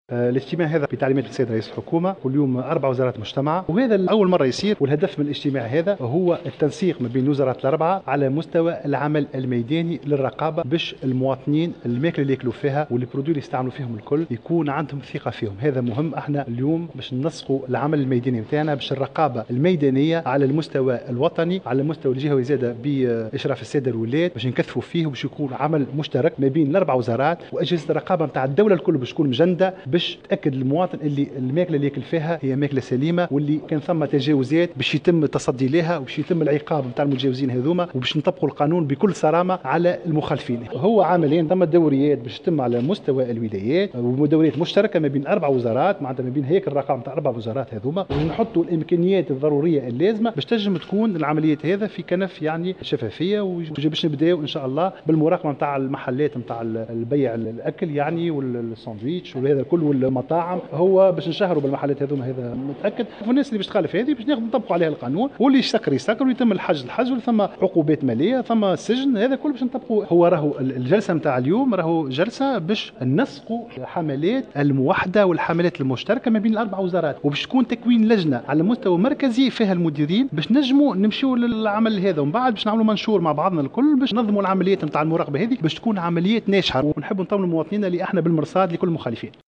وبين عمر الباهي، في تصريح لمراسلة الجوهرة أف أم، أن من بين محاور هذا الاجتماع، تكثيف العمل المشترك بين الوزارات الأربع بالتنسيق مع السلط الجهوية قصد استرجاع المواطن التونسي للثقة في المواد الإستهلاكية المروجة والتصدي لكل التجاوزات وتطبيق القانون بصرامة ضد كل المخالفين والتي ستتراوح بين قرارات الغلق وتسليط غرامات مالية والسجن.